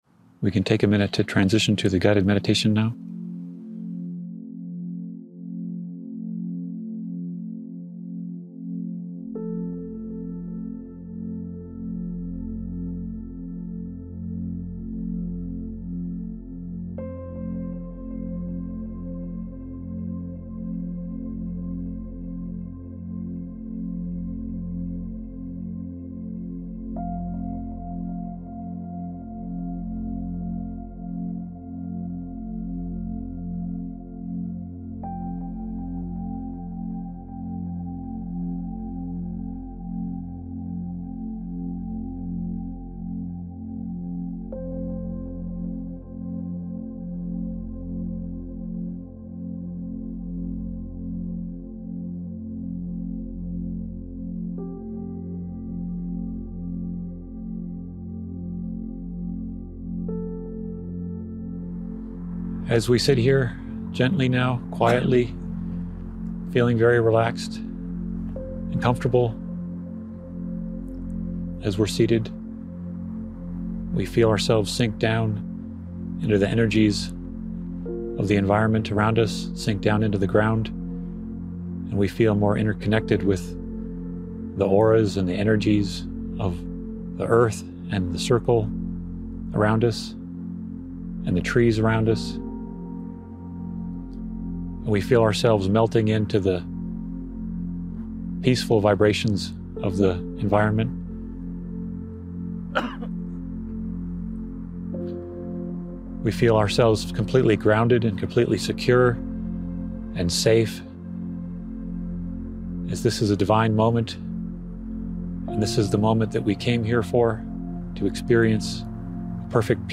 Join us for a serene guided meditation at the UFO Watchtower, where we explore the interconnectedness of our energy centers and the harmony of the cosmos, fostering unity, peace, and universal love.